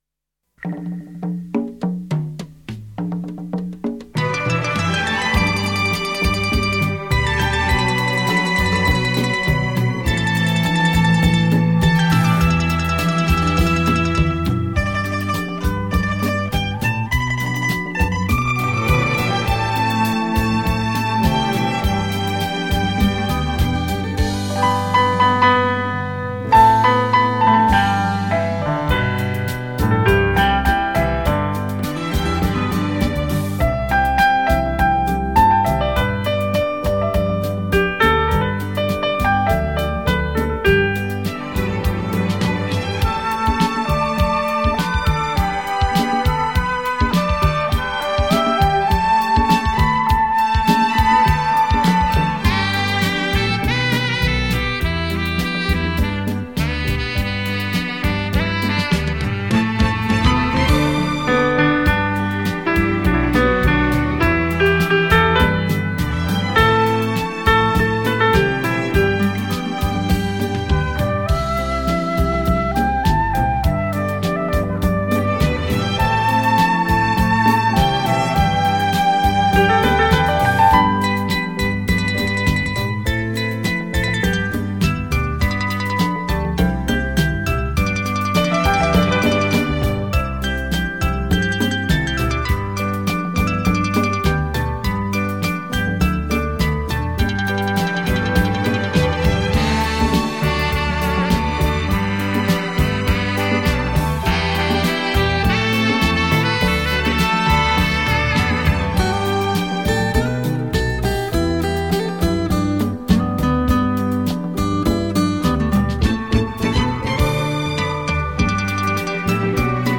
超重音 X-BASS 数位环绕音效
超时空立体动向效果 百万名琴魅力大出击